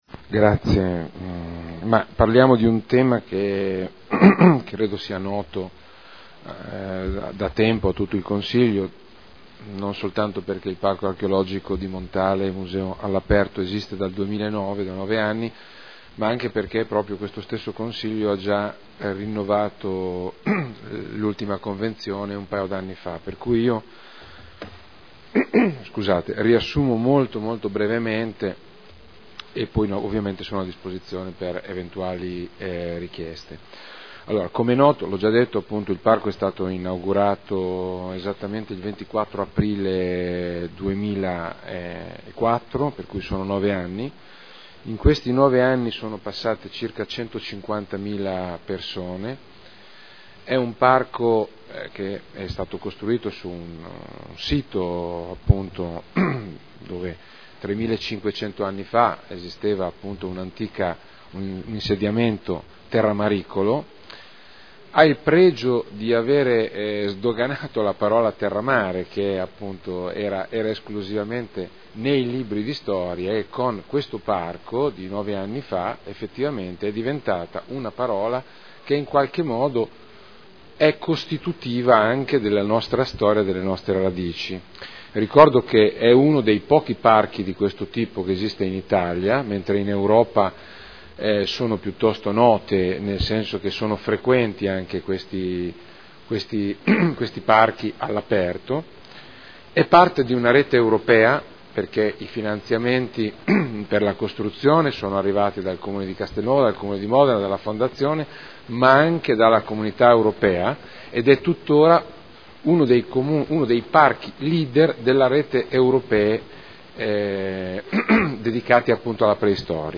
Roberto Alperoli — Sito Audio Consiglio Comunale
Seduta del 08/04/2013 Delibera.